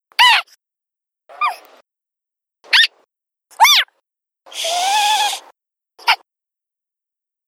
whackaMole_randomSqueak_4.wav